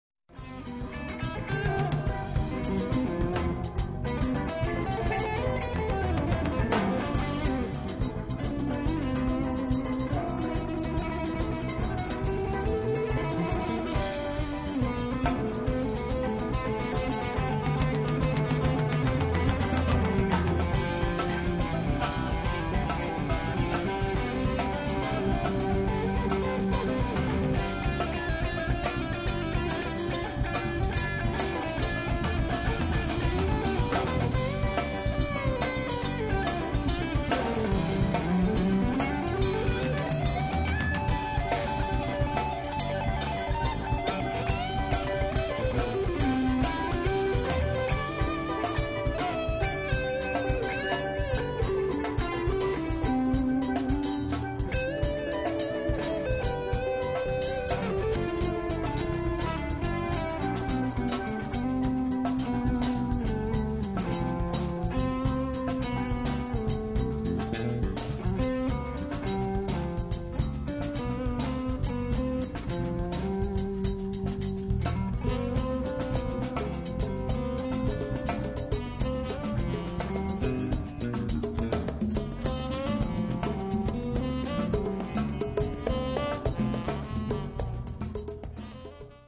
Guitars
Bass